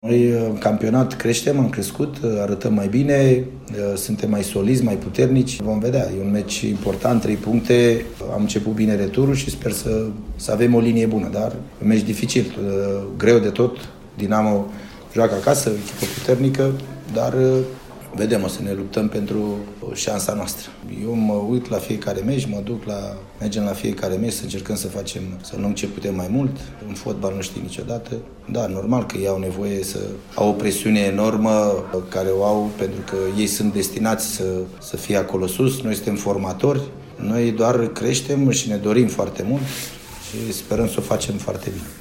Prezent la Reșița, săptămâna trecută, Gică Hagi a vorbit despre importanța acestui joc pentru echipa pe care o pregătește: